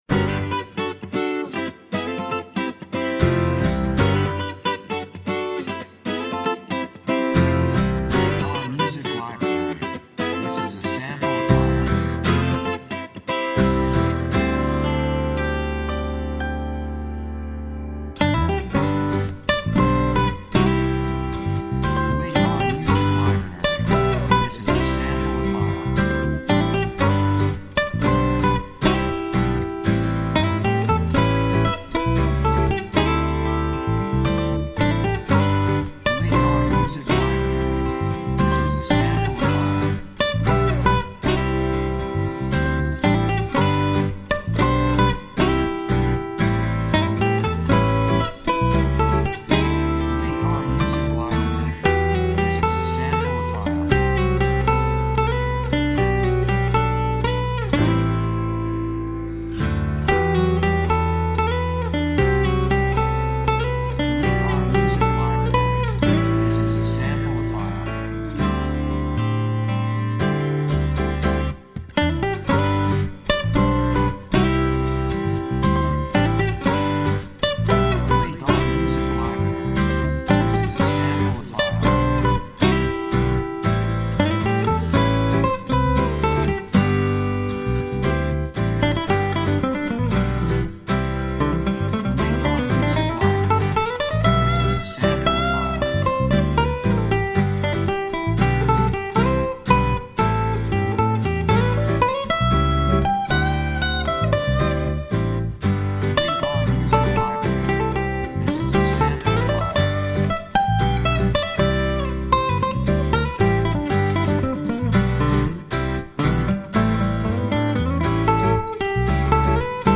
雰囲気幸せ, 穏やか, 高揚感, 感情的
楽器アコースティックギター, ピアノ
サブジャンルフォークポップ
テンポやや速い